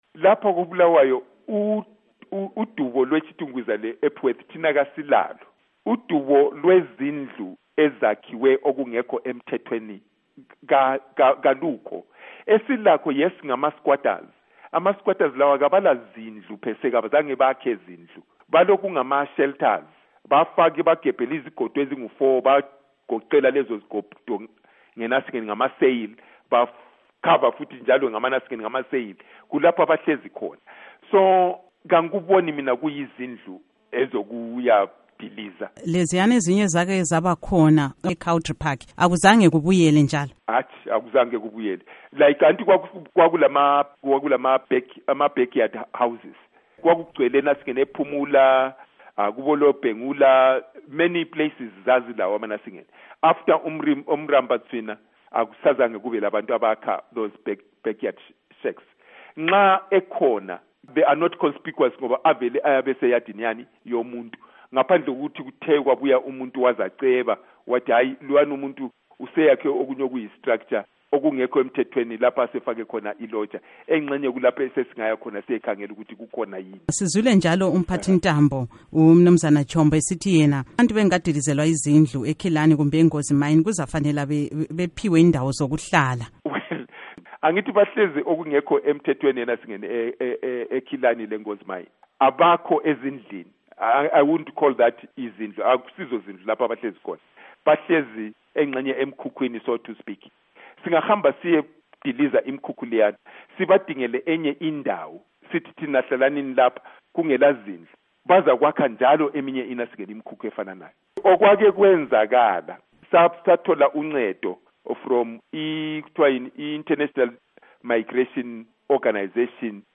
Ingxoxo LoKhansila Martin Moyo